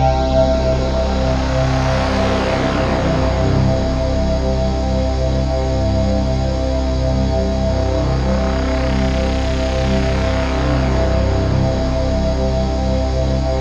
Index of /90_sSampleCDs/USB Soundscan vol.13 - Ethereal Atmosphere [AKAI] 1CD/Partition C/03-CHIME PAD
CHIMEPADC2-L.wav